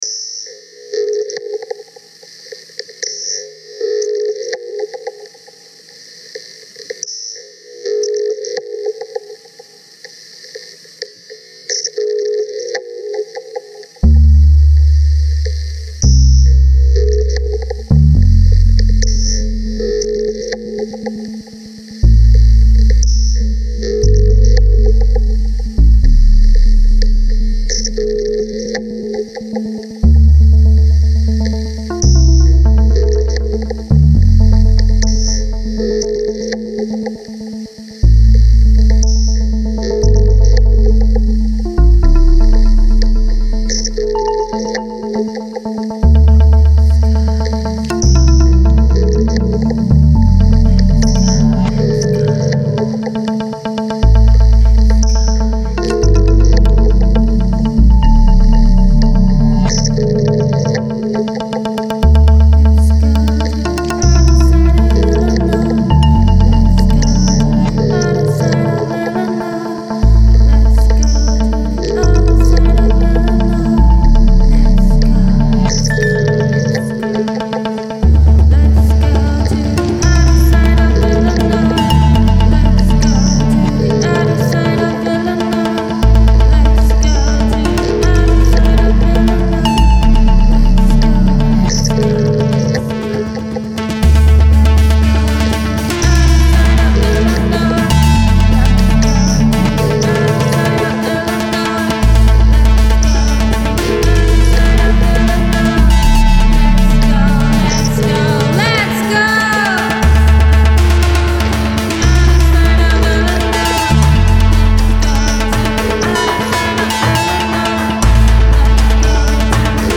einen Hauch Gesang